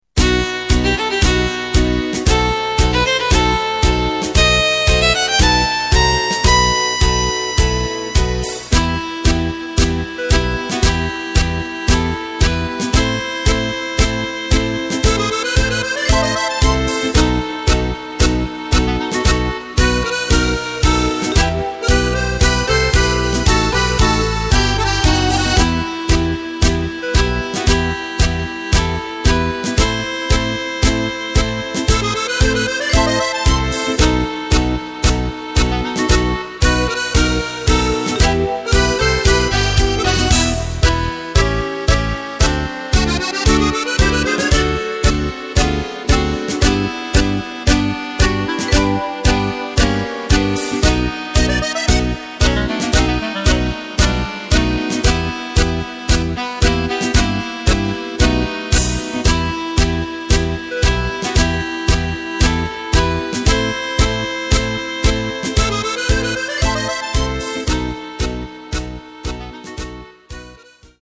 Takt:          4/4
Tempo:         115.00
Tonart:            B
Tango aus dem Jahr 1932!
Playback mp3 Demo